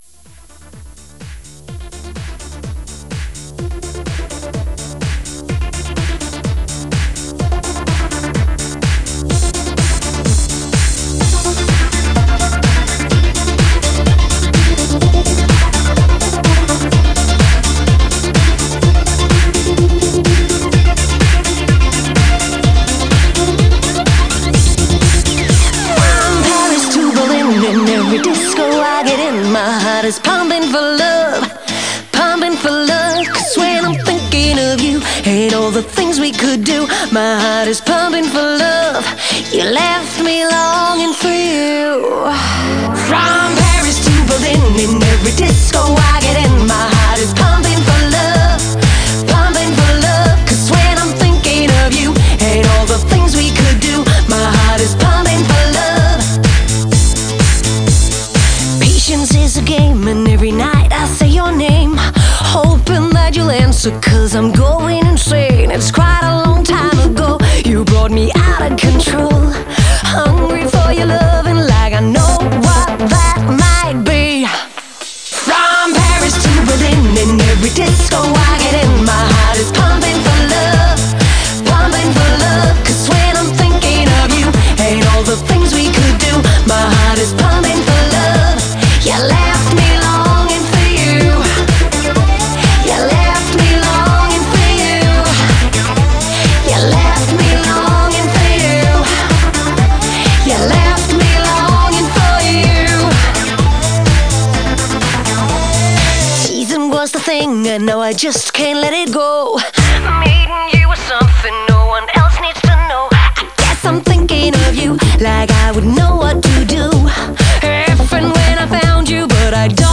disco.wav